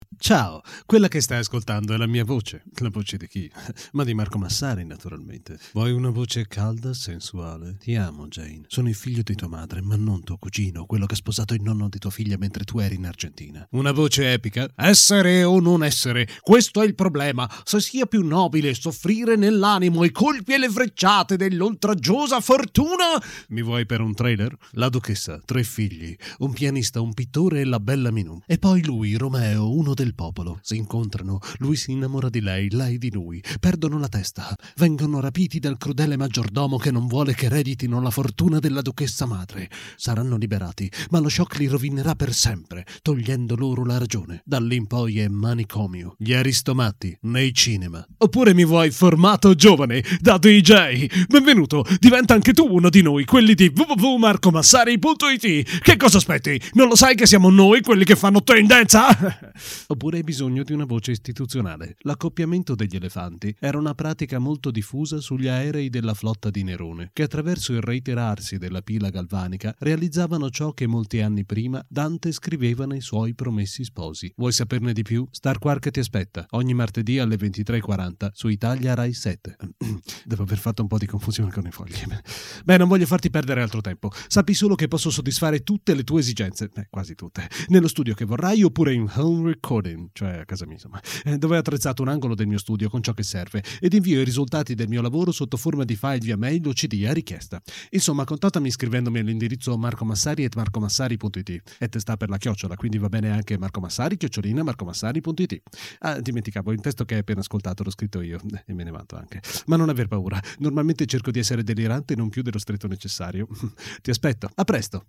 Sprechprobe: Werbung (Muttersprache):
I try to be an "any purpose" voice.